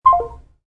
End-Dial.mp3